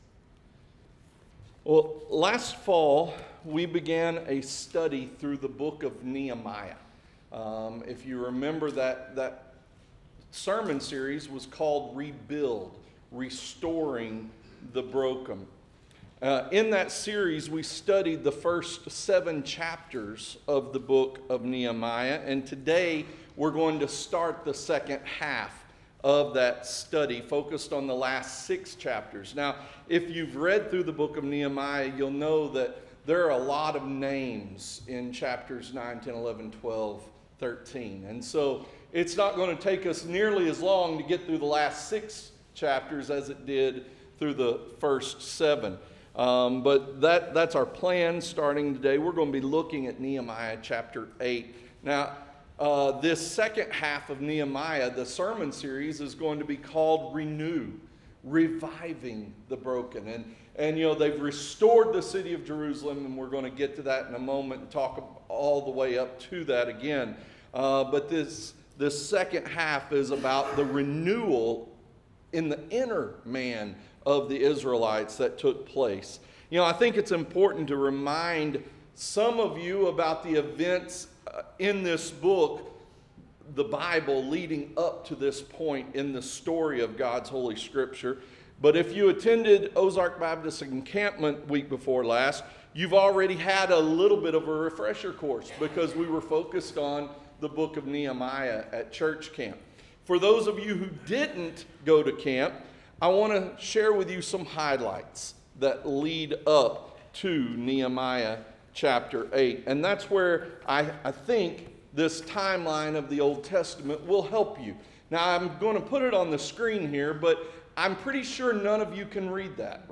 Interactive Sermon Notes Series: Nehemiah: Rebuild & Renew , RENEW: Reviving the Broken